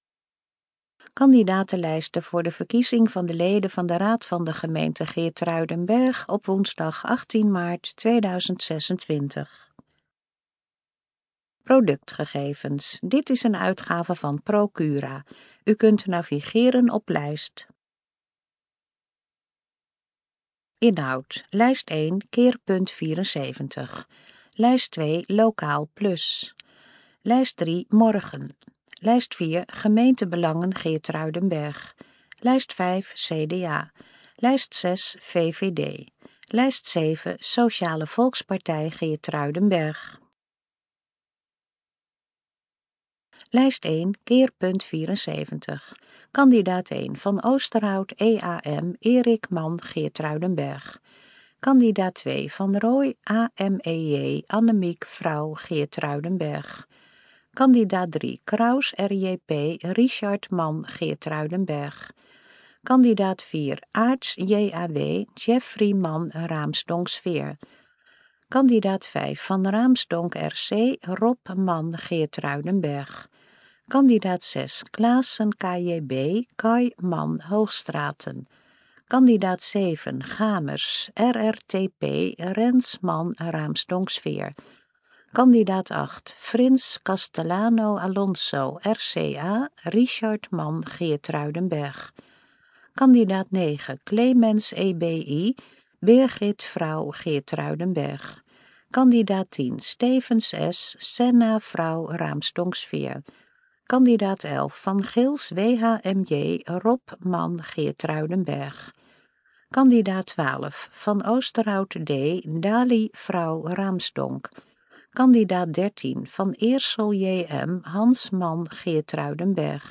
Hieronder vindt u de gesproken kandidatenlijsten
kandidatenlijst_gesproken_vorm_gr26_geertruidenberg_1.mp3